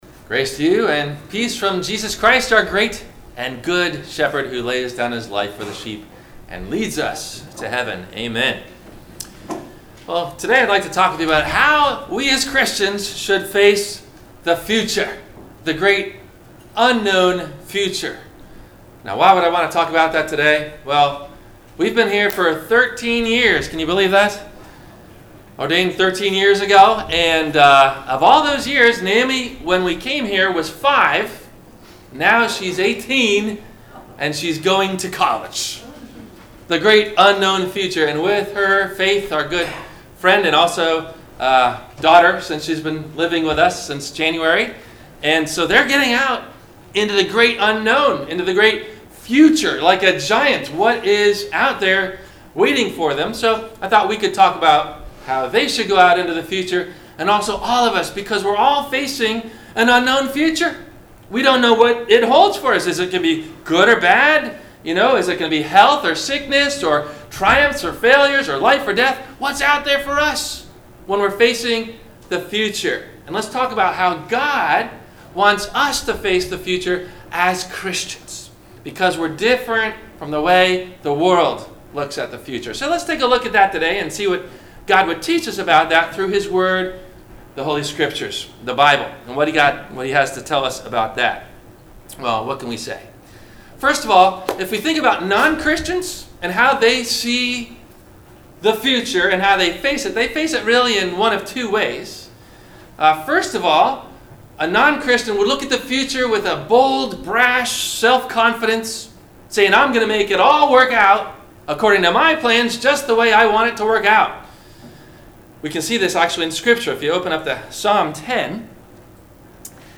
- Sermon - August 12 2018 - Christ Lutheran Cape Canaveral